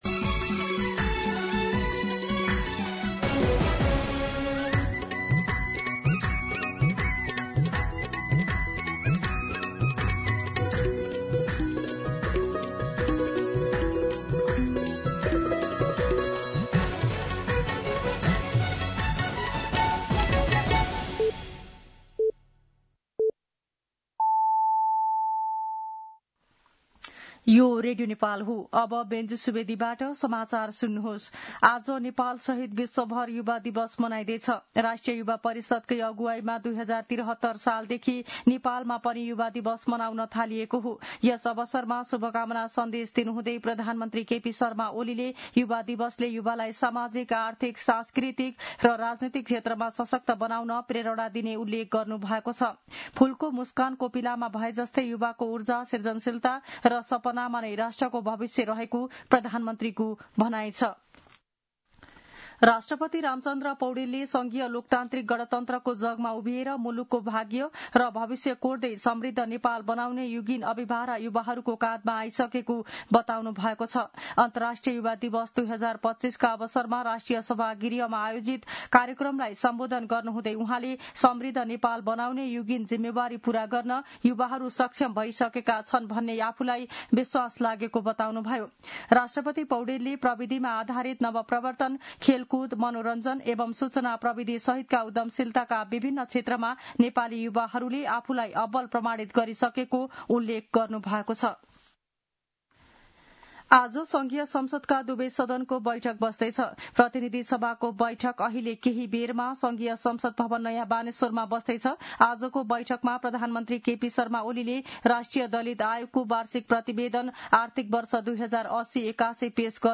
दिउँसो १ बजेको नेपाली समाचार : २७ साउन , २०८२